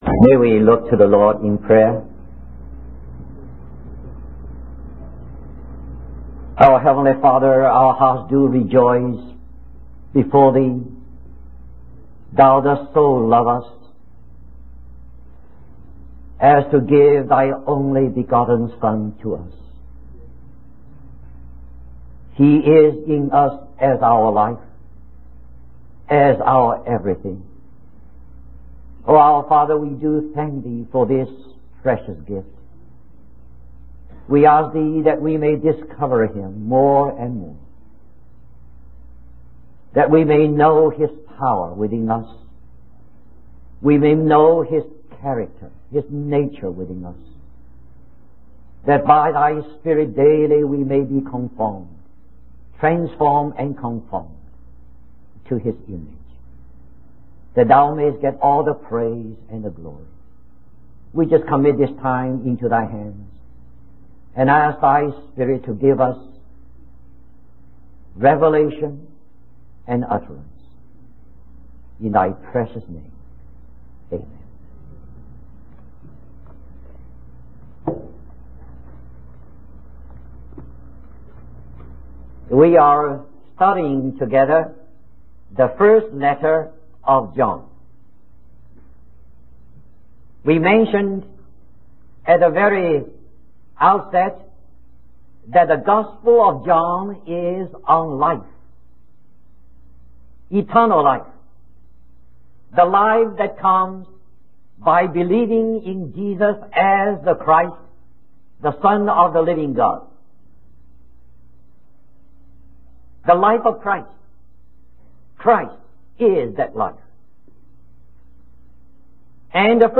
In this sermon, the speaker begins by expressing gratitude to God for the gift of His Son, Jesus Christ. The focus of the sermon is on the importance of practicing righteousness and doing the will of God. The speaker emphasizes that as children of God, we should strive to resemble our Heavenly Father by living a righteous life.